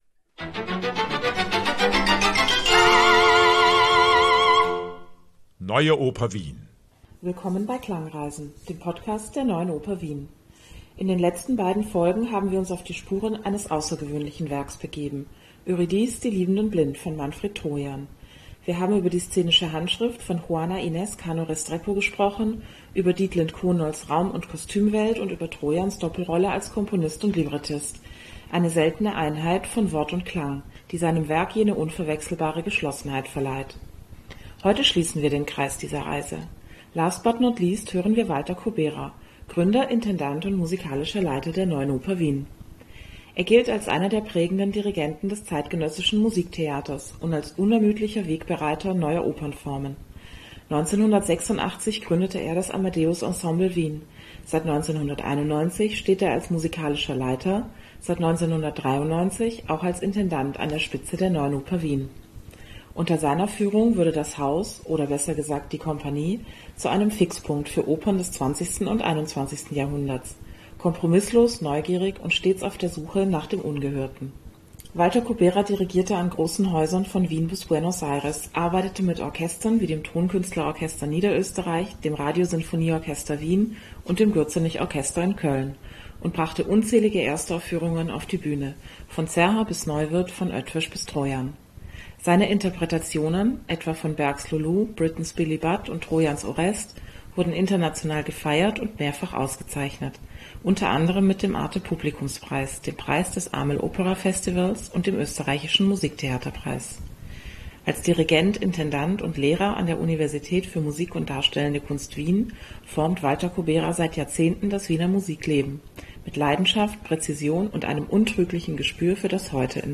Wie lässt sich Gegenwart komponieren und zugleich der Blick zurück wagen? Die für diesen Podcast verwendeten Musikbeispiele wurden dankenswerterweise von den Archivaufnahmen der De Nationale Opera & Ballet Amsterdam zur Verfügung gestellt.